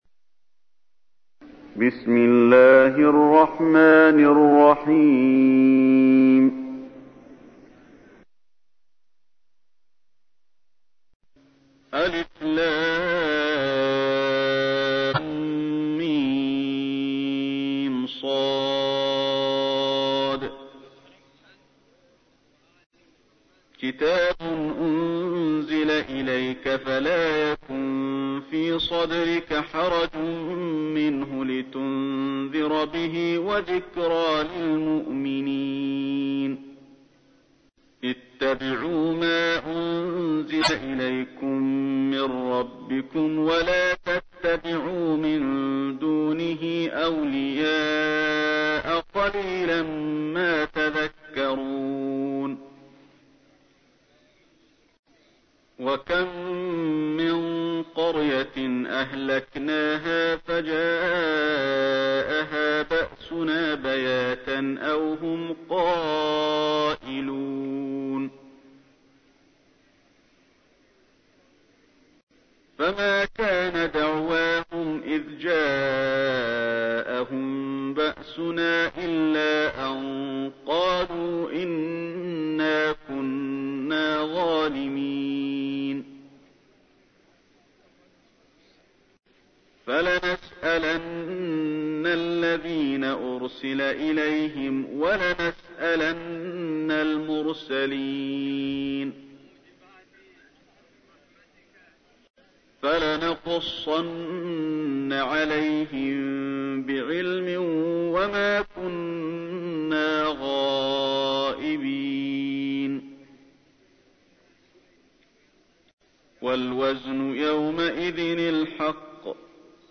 تحميل : 7. سورة الأعراف / القارئ علي الحذيفي / القرآن الكريم / موقع يا حسين